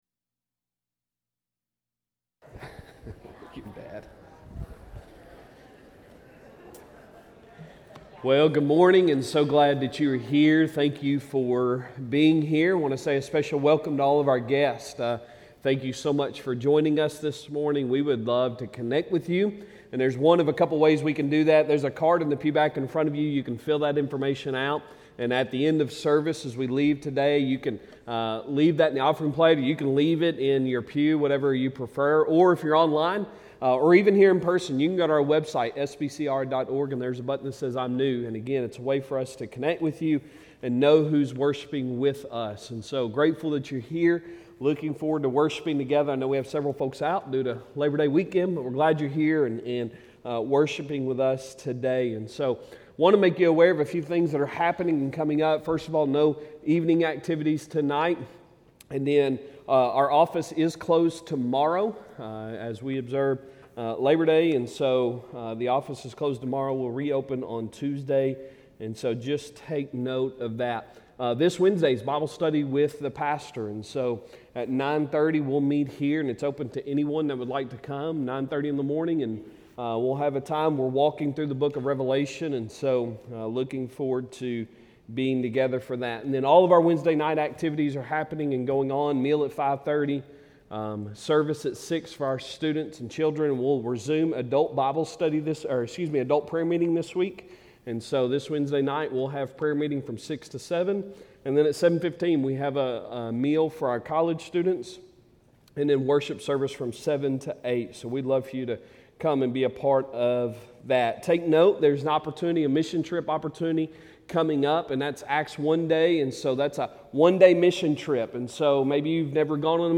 Sunday Sermon September 3, 2023